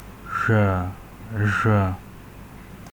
labzd voiced alveolo-palatal sibilant
[ʑʷ] Abkhaz, Ubykh
Labialized_voiced_alveolo-palatal_sibilant.ogg.mp3